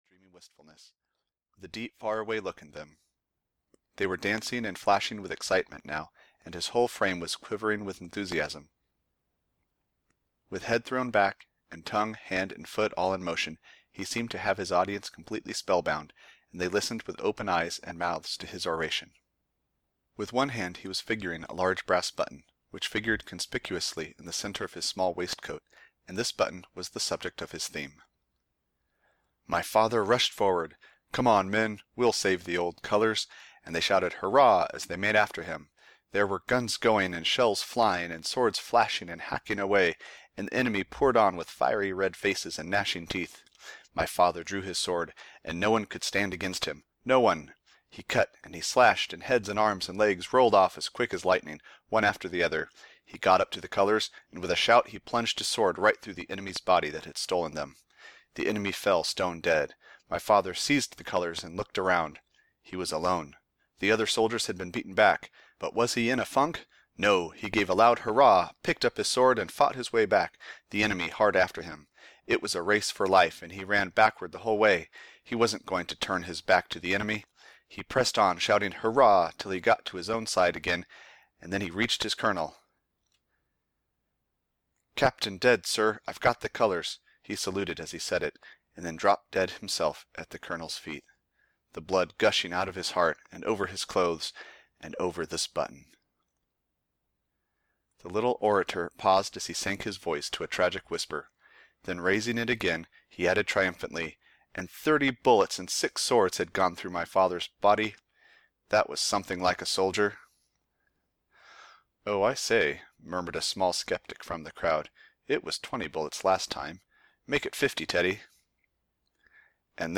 Teddy's Button (EN) audiokniha
Ukázka z knihy